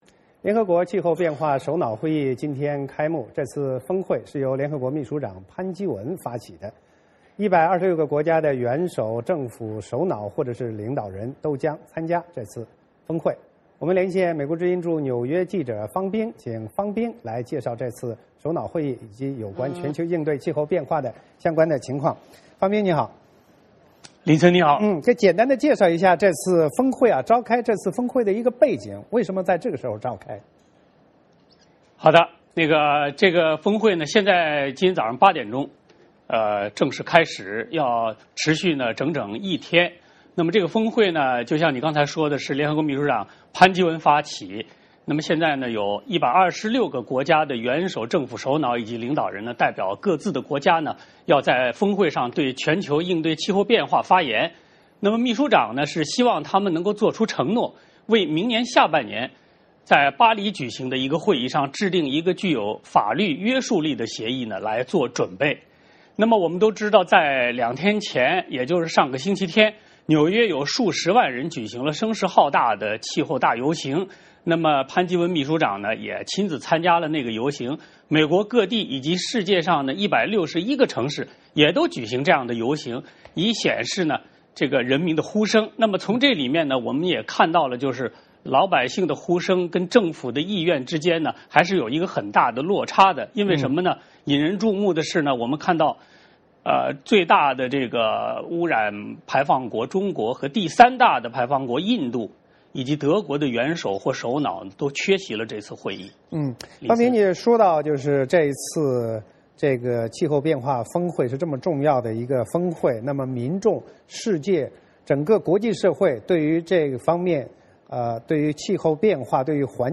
VOA连线：联合国气候变化峰会开幕